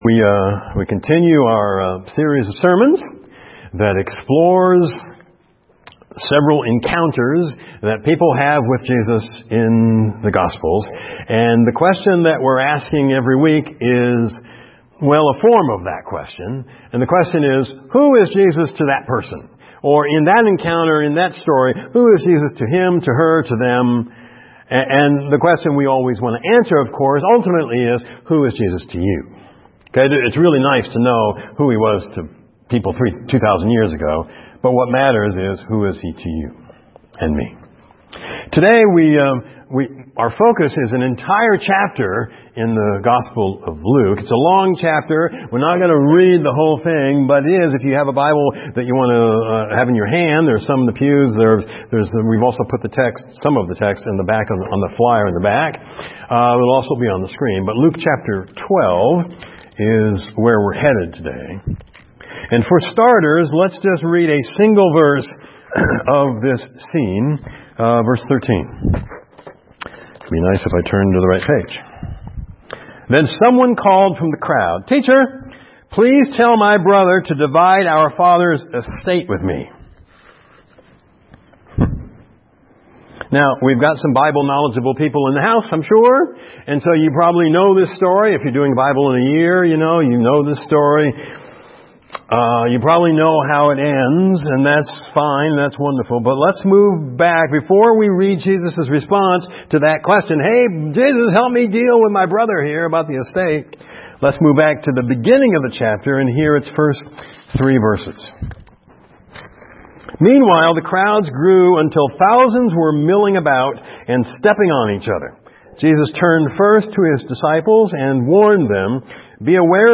FCCEM Sermon Audio Files - First Christian Church of East Moline